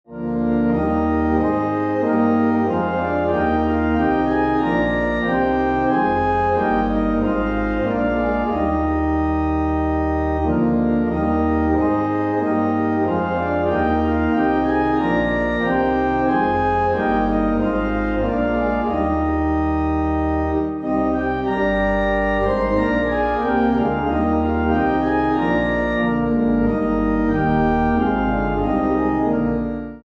Organ
Eb